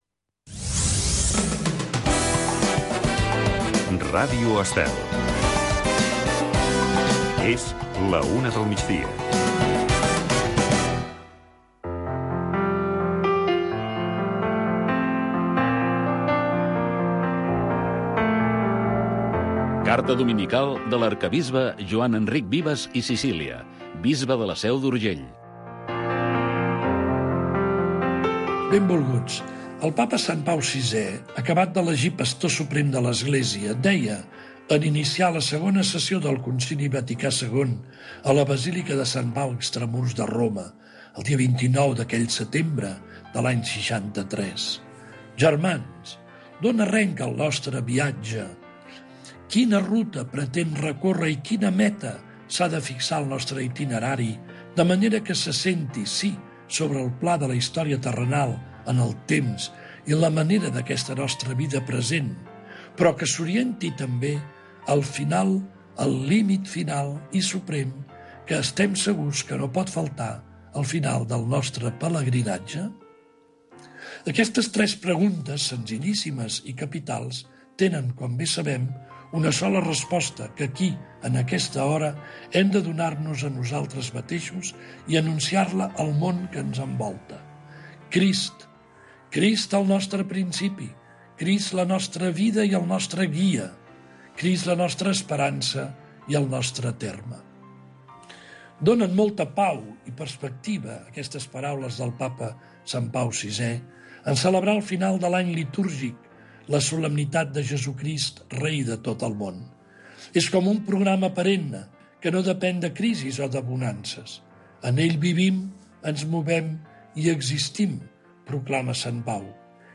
Espai amb d'entrevistes als protagonistes més destacats de cada sector, amenitzat amb la millor música dels 60, 70, 80 i 90